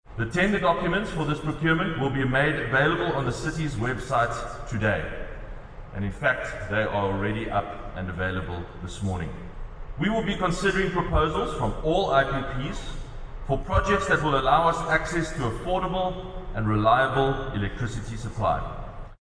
The mayor was addressing delegates at the Solar Power Africa conference at the CTICC.